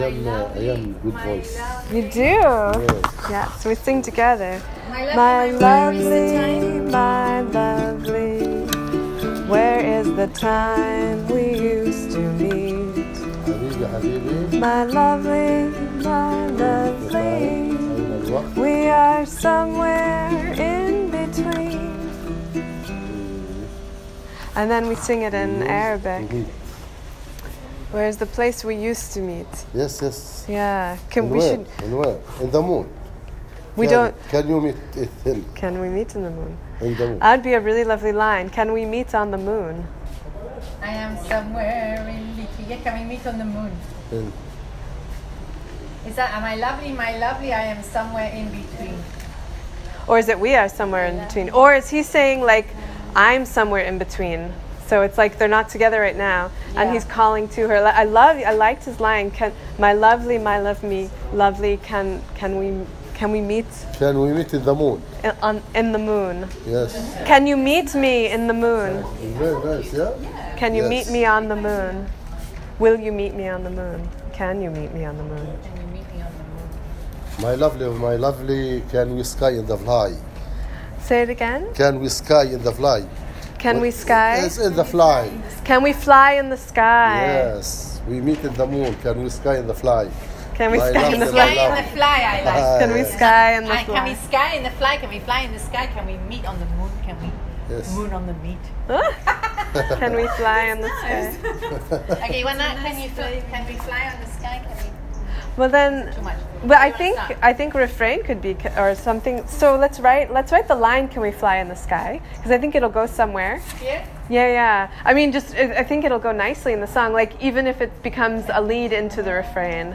This recording offers glimpses into the writing of the song.
my-lovely-habibi-writing-the-song.m4a